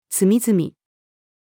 隅々-female.mp3